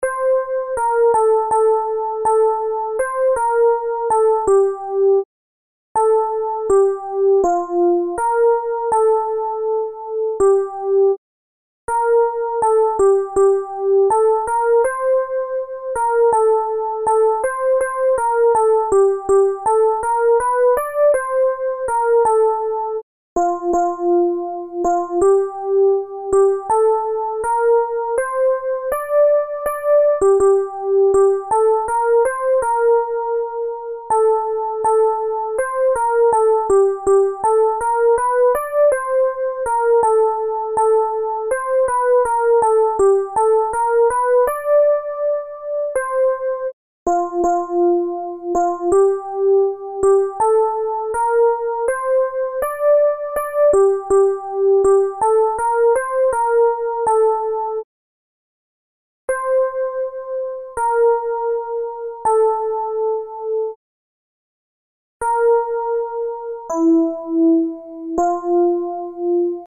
Soprani
due_pupille_soprani.MP3